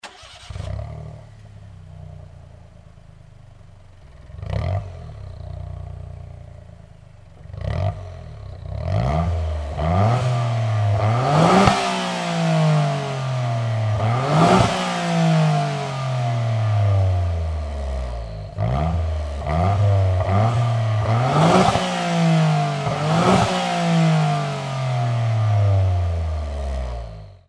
柿本改のGTbox06&S（空吹かし）
copen_la400-kakimoto_acceleration.mp3